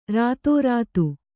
♪ rātōrātu